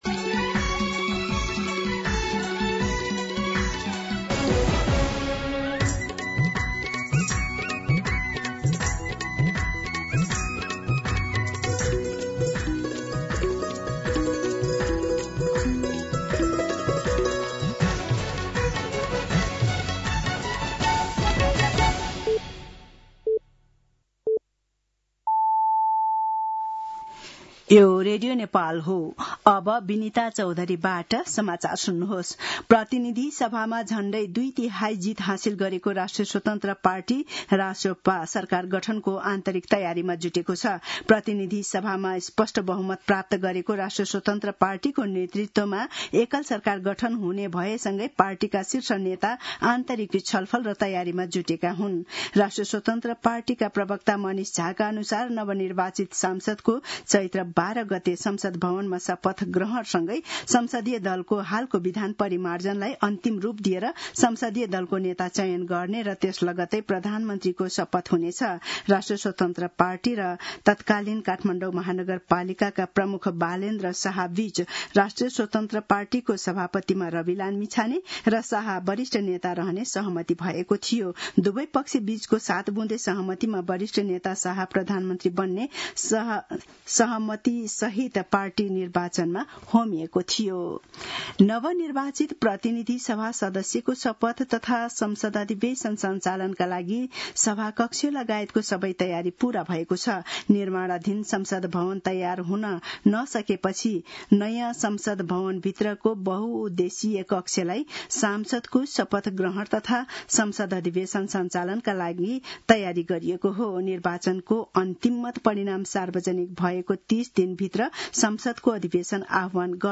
दिउँसो १ बजेको नेपाली समाचार : ८ चैत , २०८२
1-pm-Nepali-News-5.mp3